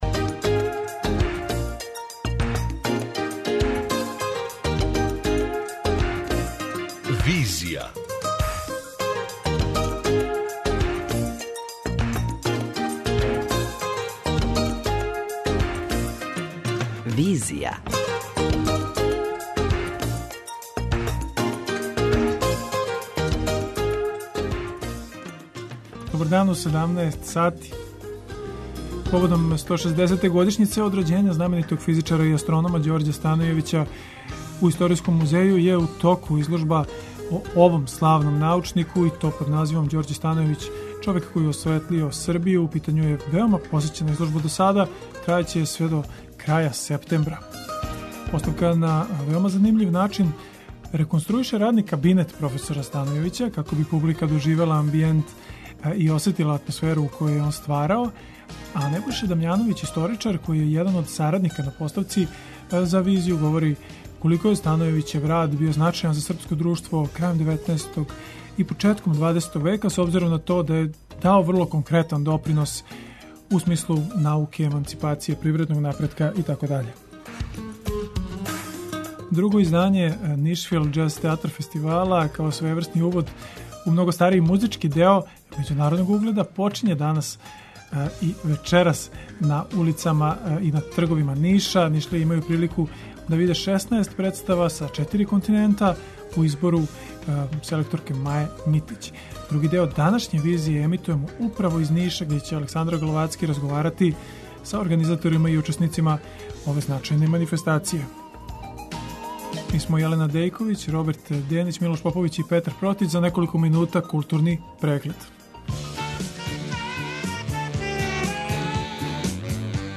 Други део данашње Визије емитујемо из Ниша